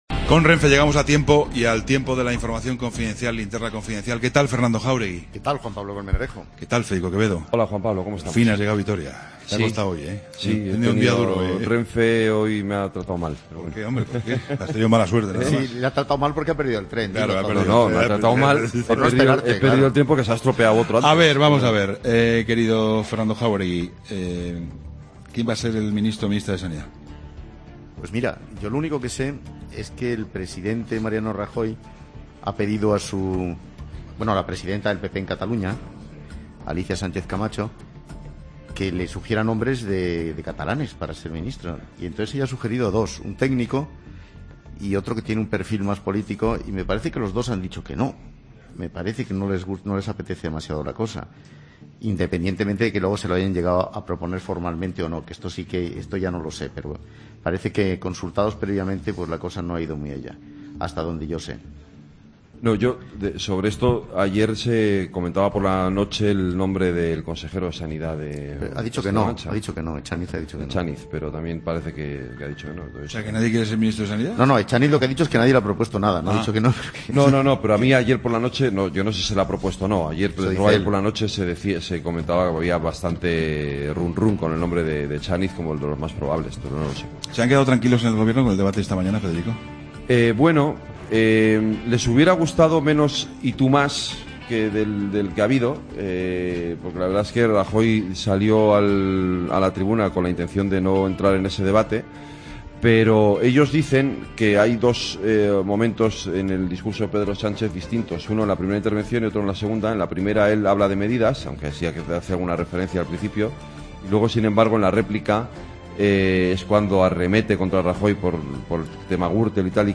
Incluye entrevista a Javier Maroto, alcalde de Vitoria.